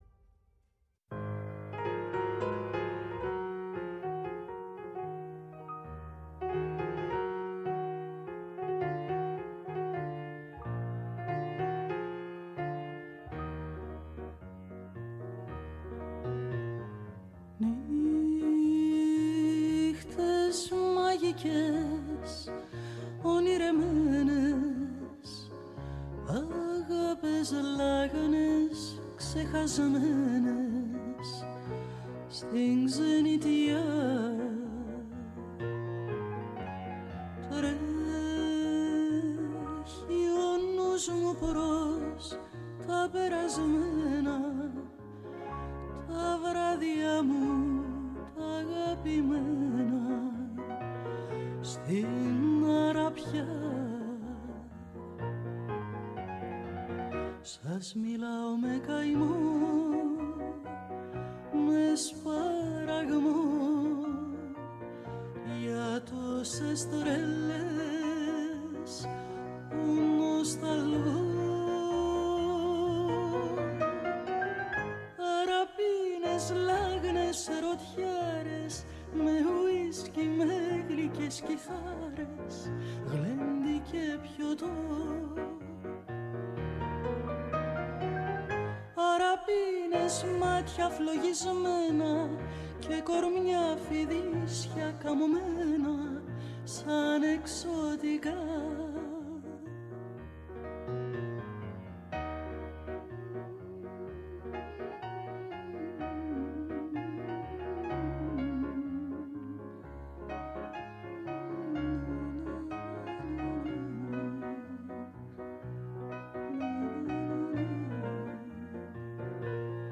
Στο studio της “Φωνής της Ελλάδας”